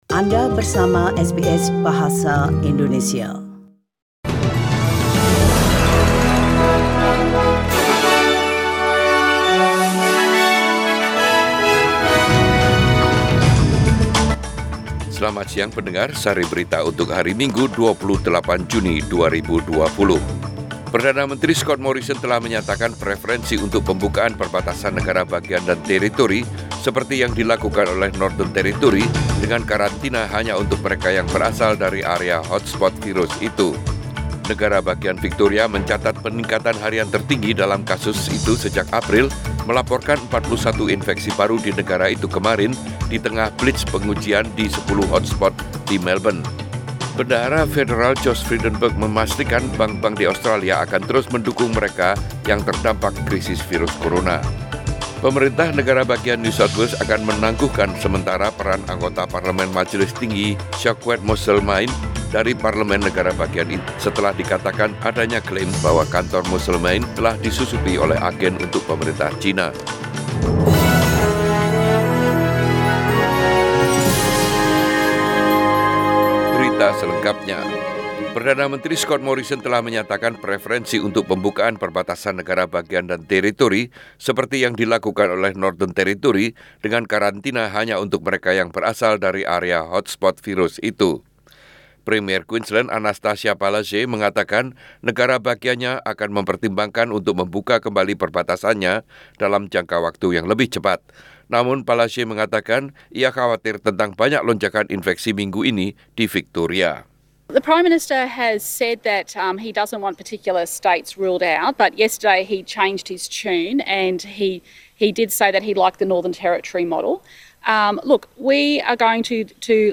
SBS Radio News in Bahasa Indonesia - 28 June 2020